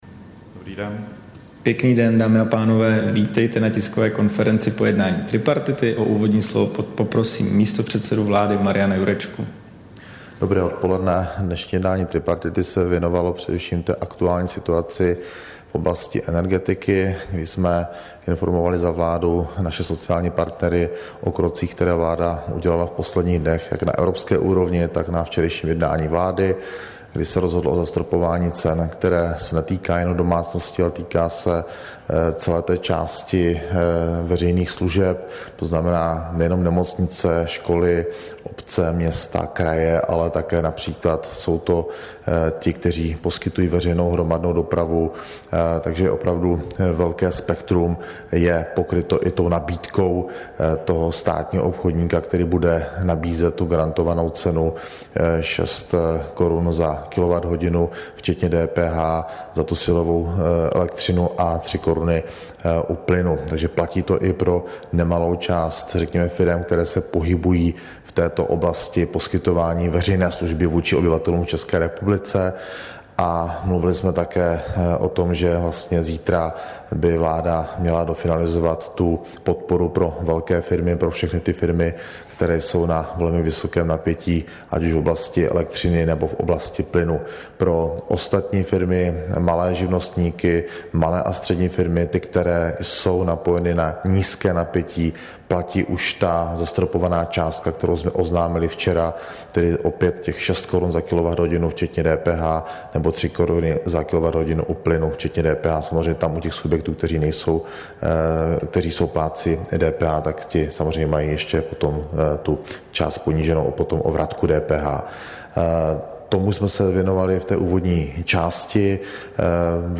Tisková konference po jednání tripartity, 13. září 2022